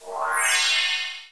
button_success_done
button_success_done.mp3